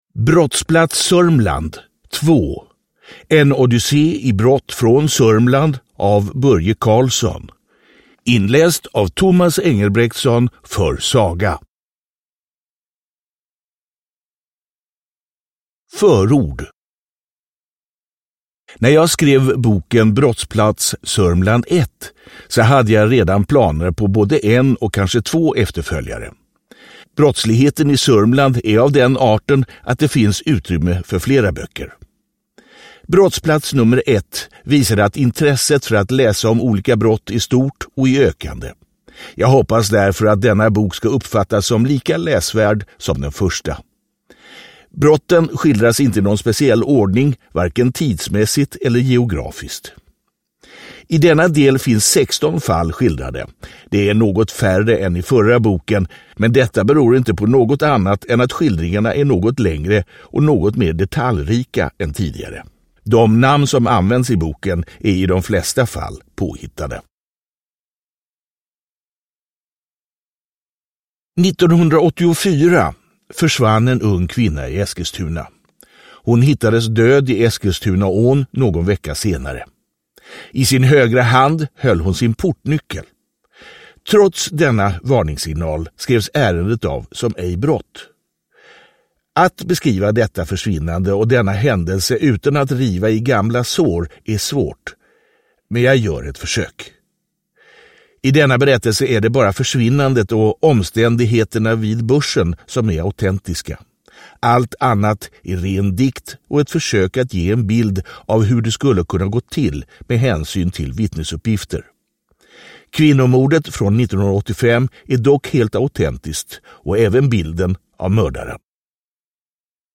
Brottsplats Sörmland. 2, En odyssé av brott – Ljudbok – Laddas ner